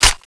Index of /server/sound/weapons/tfa_cso/m95tiger